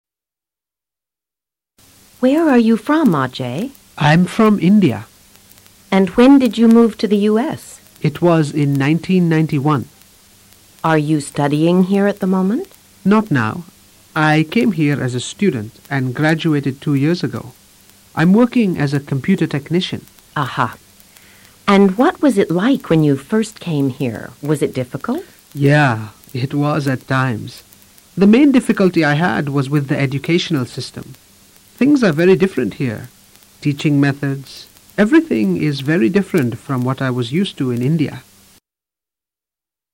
Ajay es entrevistado por una oficial de inmigración.